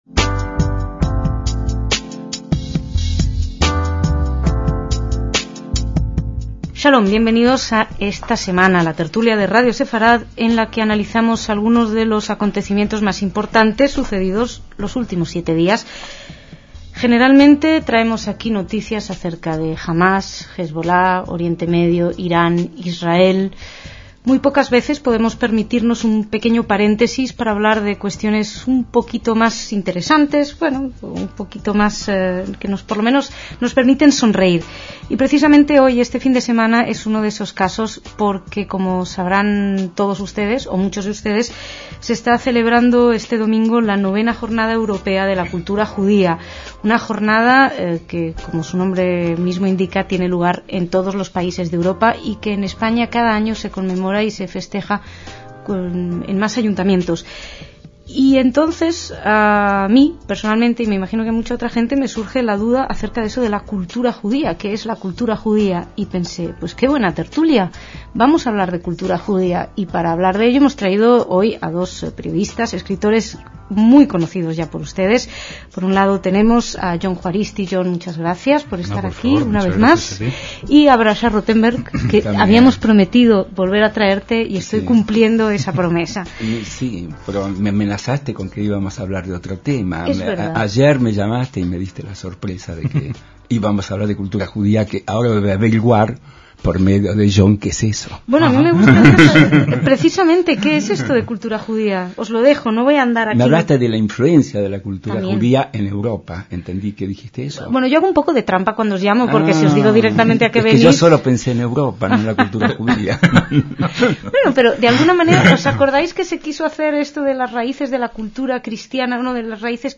Dos grandes expertos de las raíces judías de la cultura europea debatieron en esta tertulia